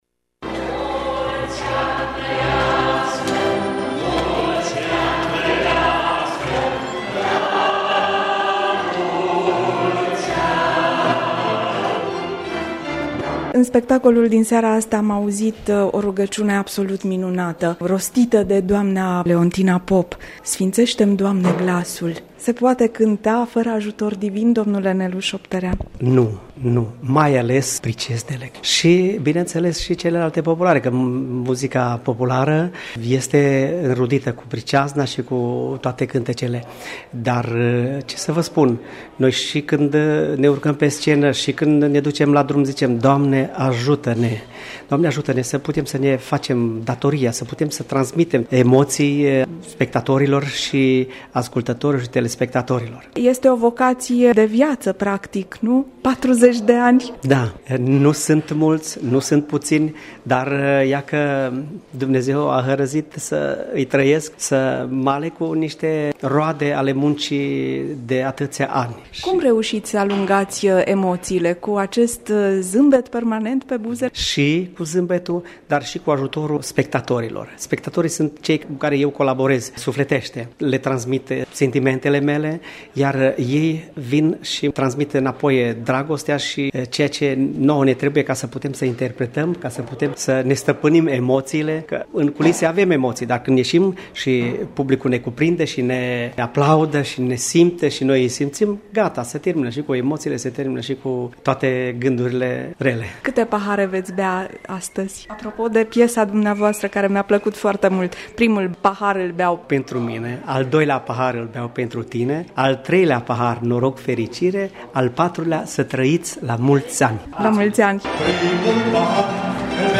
Colegi de cânt, câțiva prieteni-invitați și publicul fidel l-au recompensat cu ce au avut ei mai bun: aplauze și flori,distincții,diplome și urări “asortate cu grijă într-un buchet de gânduri alese”.